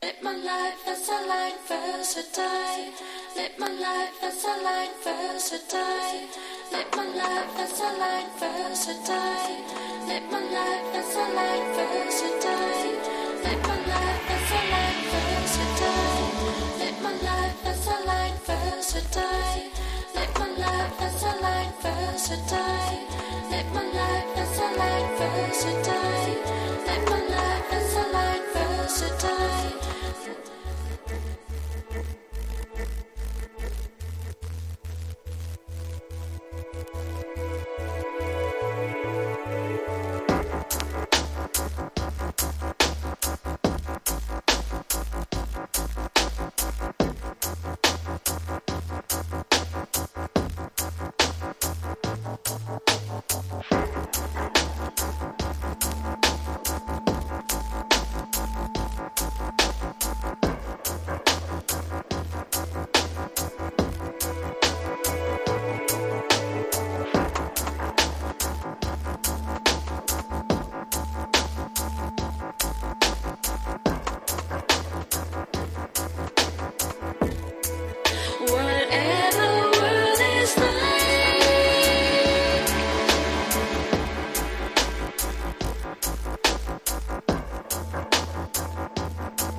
# DUB / LEFTFIELD# DUB / UK DUB / NEW ROOTS